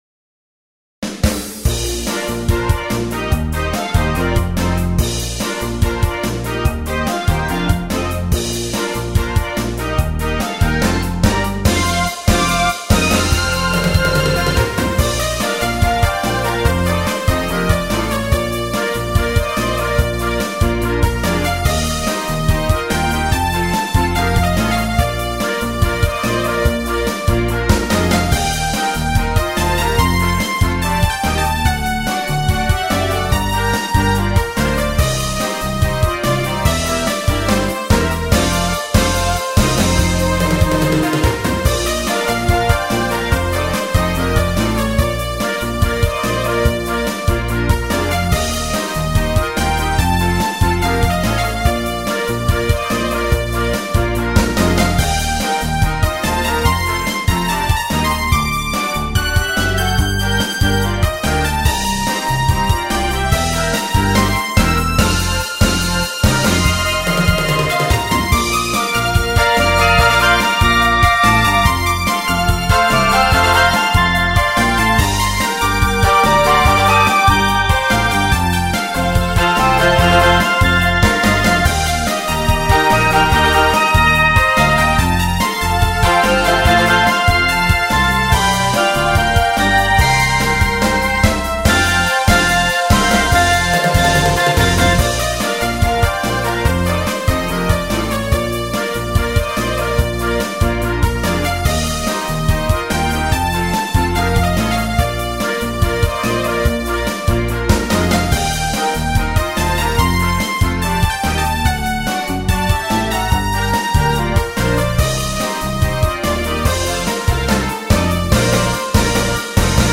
BGM
アップテンポインストゥルメンタルロング明るい